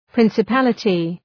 Προφορά
{,pri:nsı’pælətı} (Ουσιαστικό) ● ηγεμονία